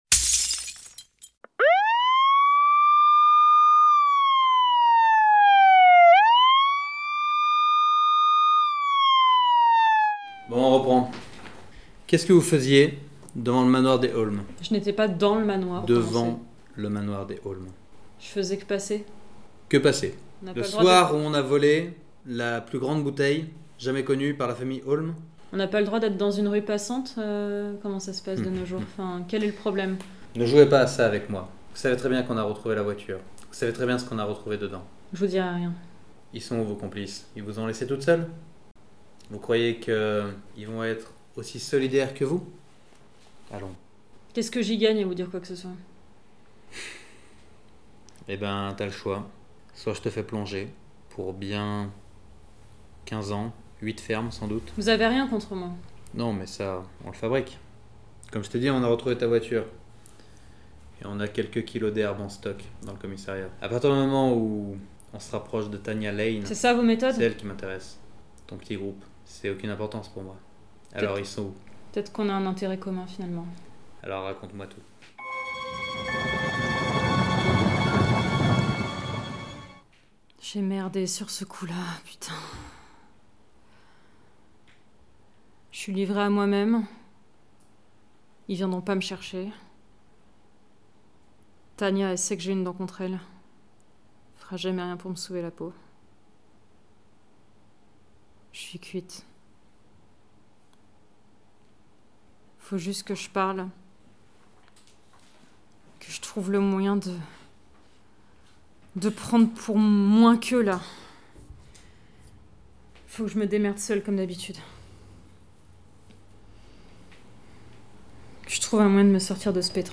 Intrigue sonore